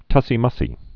(tŭsē-mŭsē)